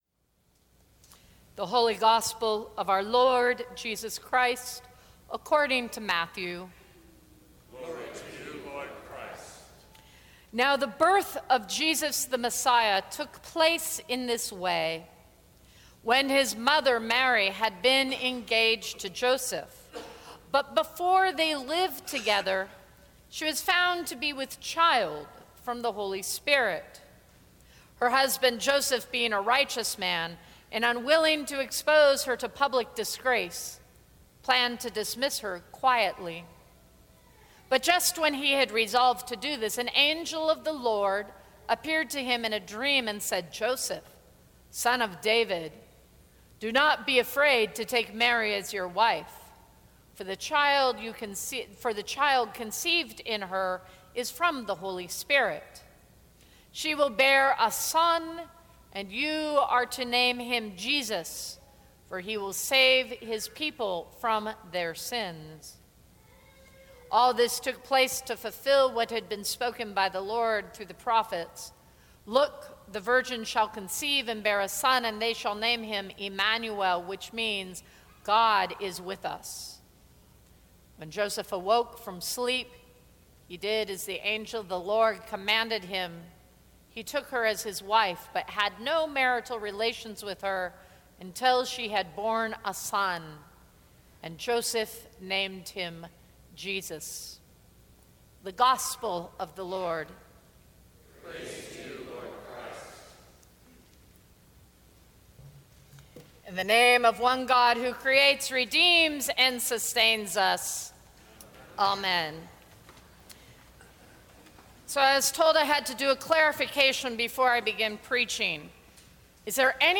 Sermons from St. Cross Episcopal Church The Cherry Tree Carol Dec 18 2016 | 00:16:43 1x 00:00 / 00:16:43 Subscribe Share Apple Podcasts Spotify Overcast RSS Feed Share Link Embed